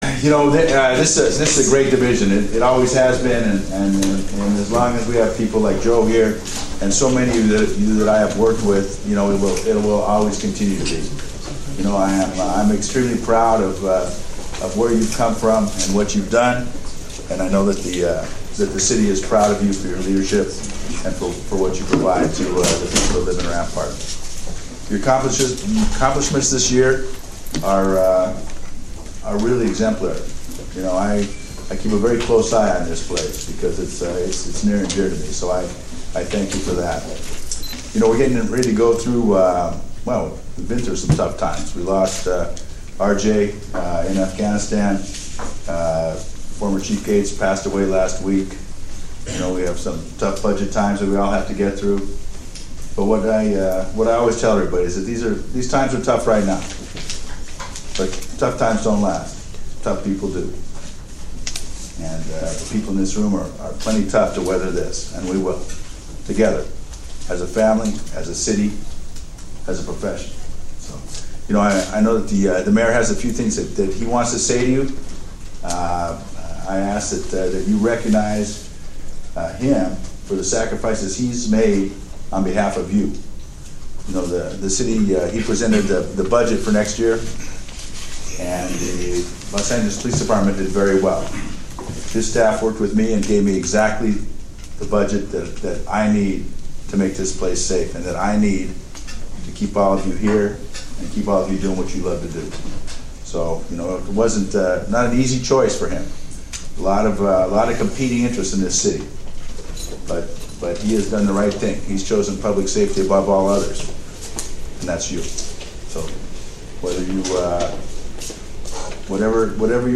April 21, 2025 – With officers gathered for their pre-shift briefing in the roll call room at Rampart Community Police Station, Chief Charlie Beck and Mayor Antonio Villaraigosa took the opportunity to speak Rampart_Roll_Call on the City’s budget situation and reaffirm their commitment to public safety.
To hear Chief Beck’s address to his officers,
ramp_roll-call.mp3